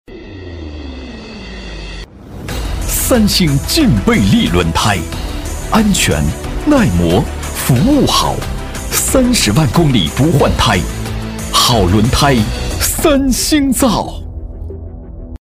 男72-广告配音【三星轮胎 大气昂扬】
男72-广告配音【三星轮胎 大气昂扬】.mp3